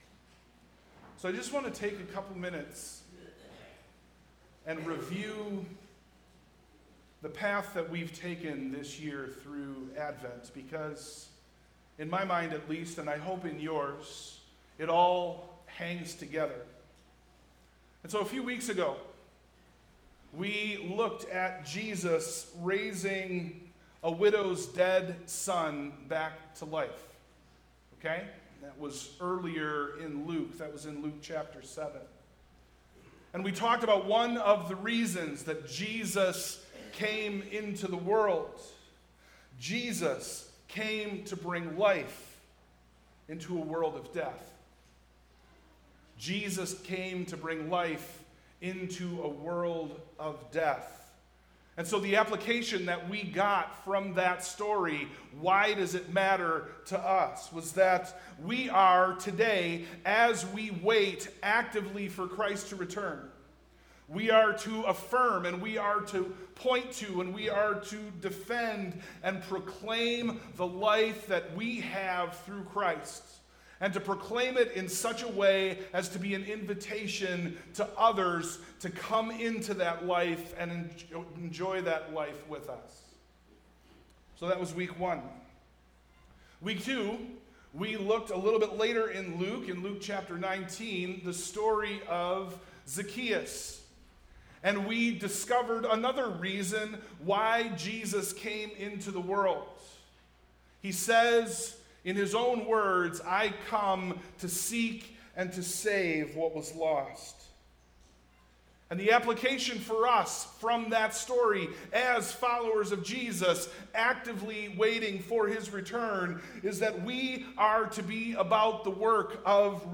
Sermon+Audio+-+The+Coming+Son+Of+Man.mp3